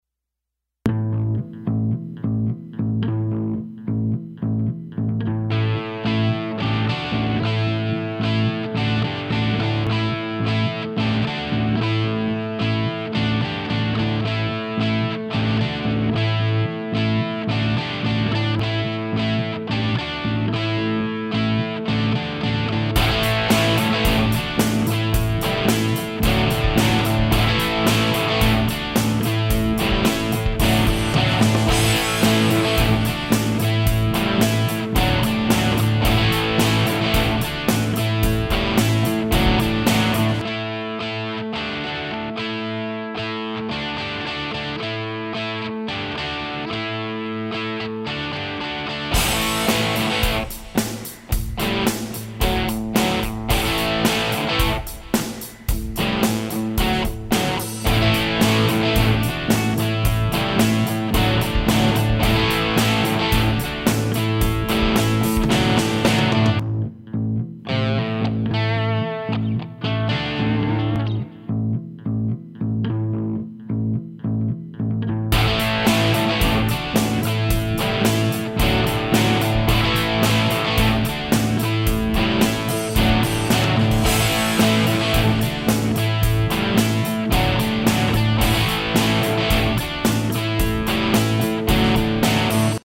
guitar hero - melancolie - rock - guitare electrique - live